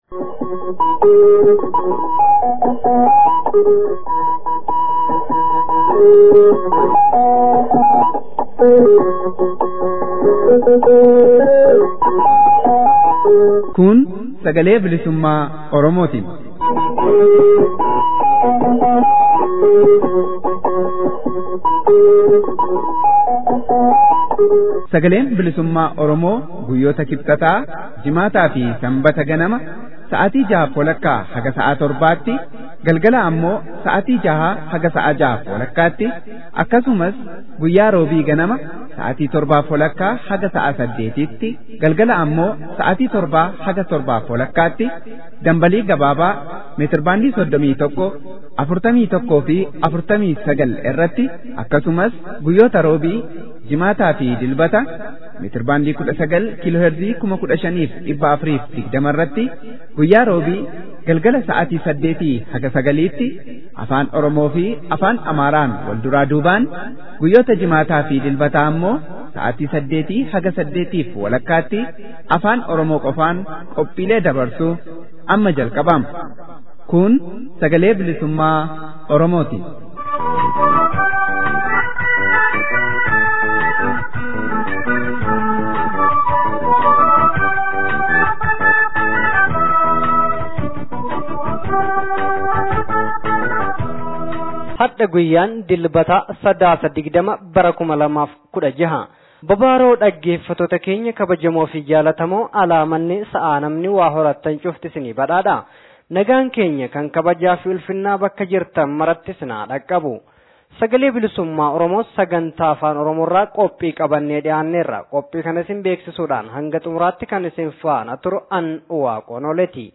SBO: Sadaasa 20 bara 2016. Oduu